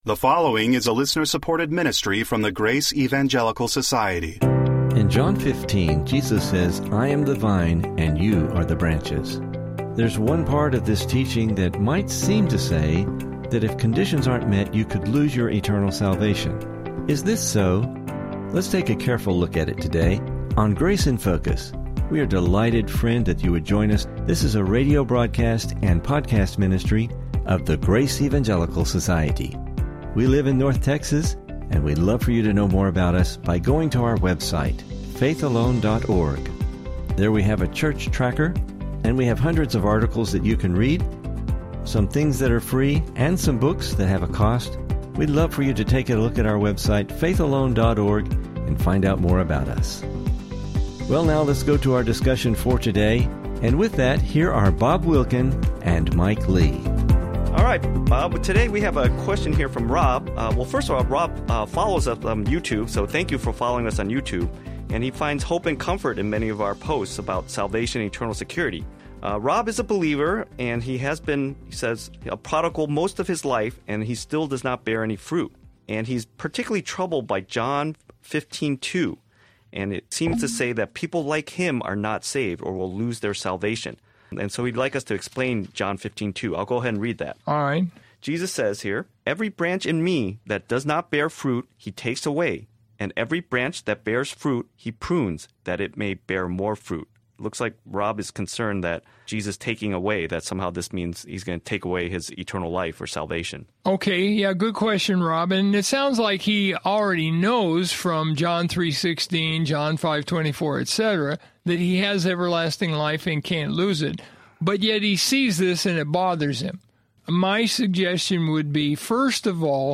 Please listen for an interesting discussion and lessons related to this topic.